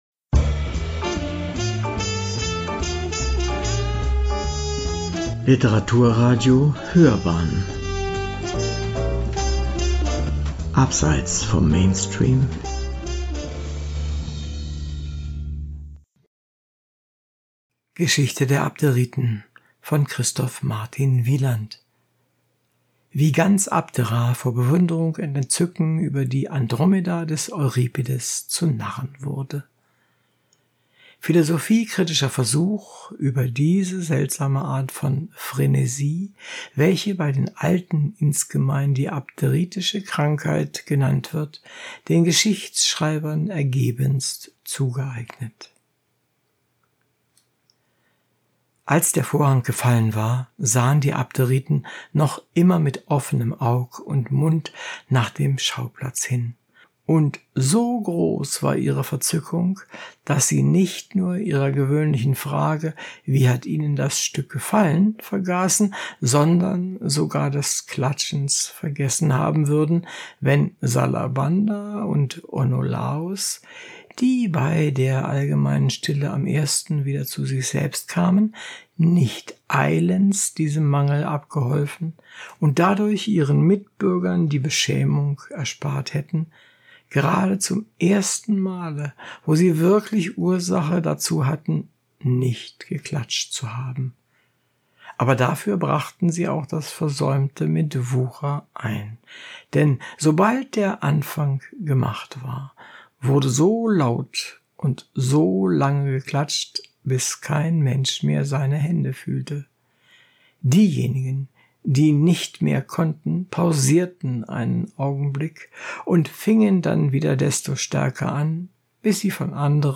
Gemeinsam mit vielen unserer talentierten Sprecherinnen und Sprecher haben wir das Buch “Geschichte der Abderiten” von Christoph Martin Wieland vertont.